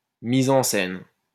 Mise-en-scène (French pronunciation: [miz ɑ̃ sɛn]